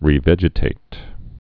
(rē-vĕjĭ-tāt)